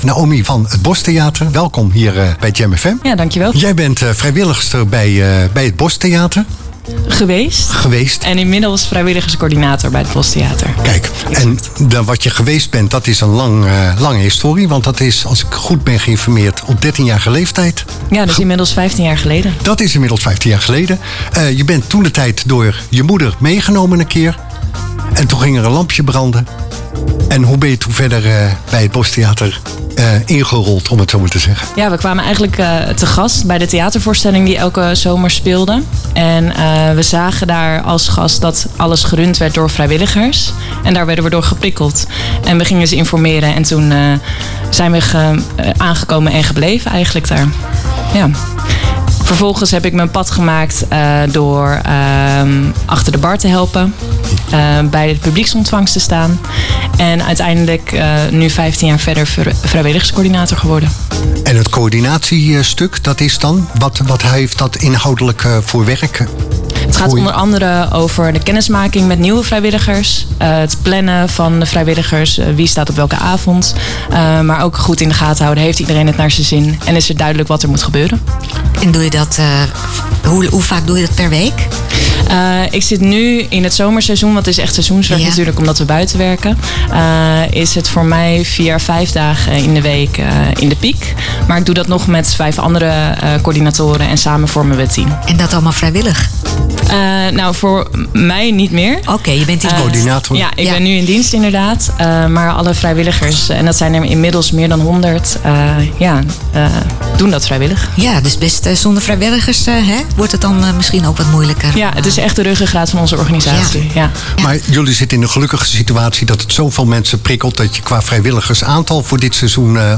Tijdens de live-uitzending van Start Me Up vanaf Aan De Plas